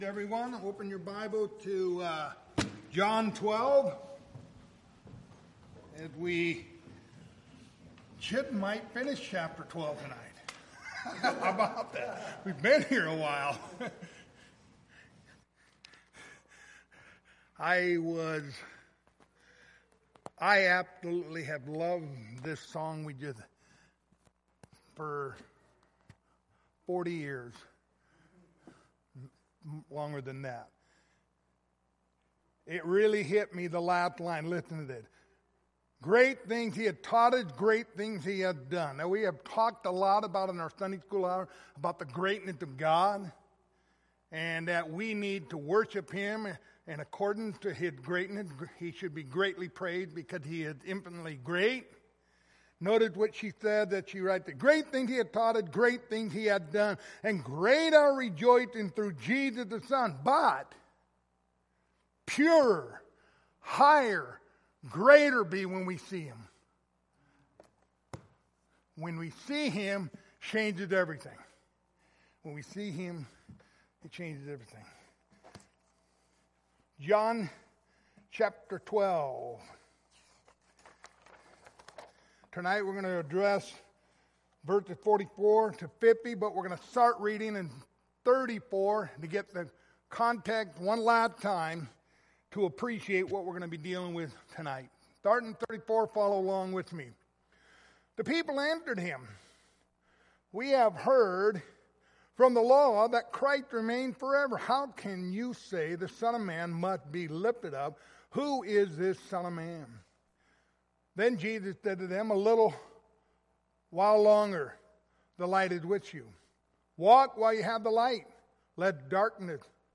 Passage: John 12:44-50 Service Type: Wednesday Evening